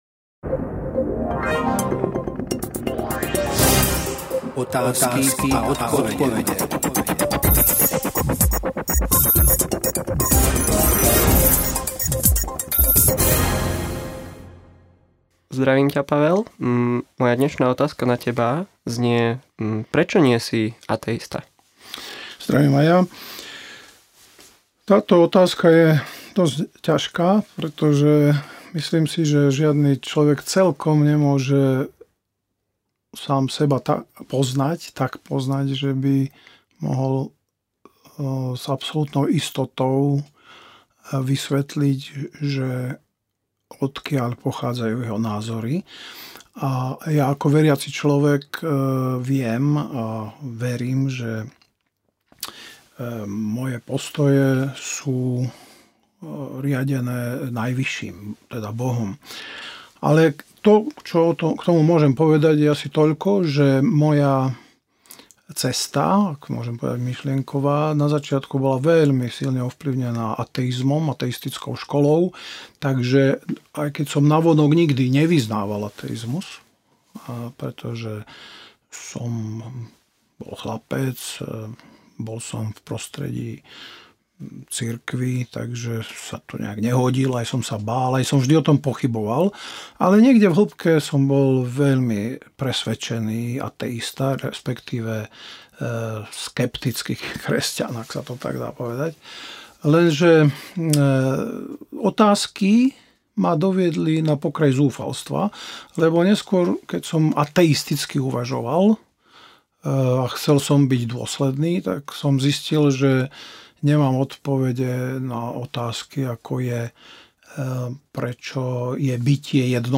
E-mail Podrobnosti Kategória: Rozhovory Séria